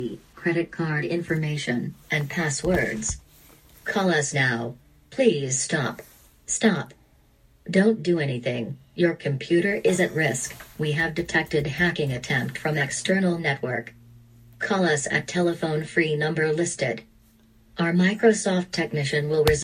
"Evil voice" on Macs - Pizzo Computer Consulting
Recently, several customers have told me about hearing a "Voice" come from their computer telling them it is infected and to call a phone number.